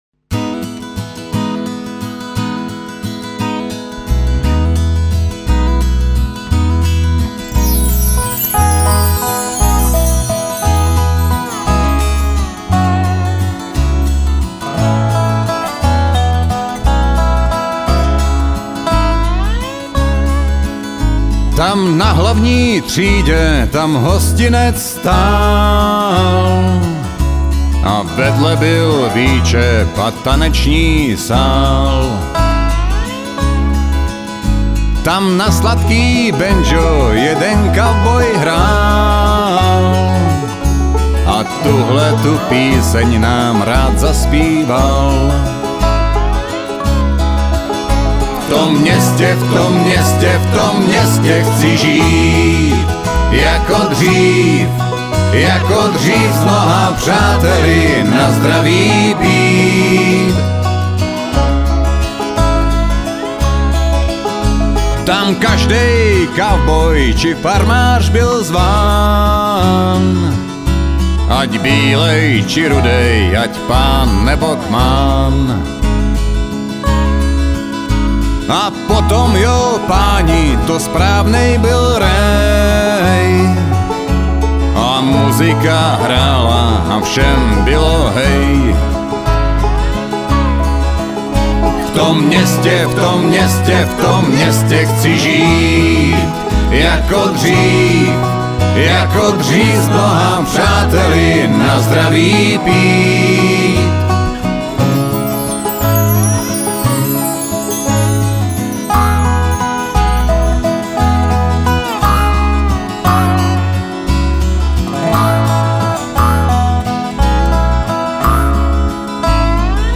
"salonní country"
bohatý repertoár (country, folk, bluegrass ...)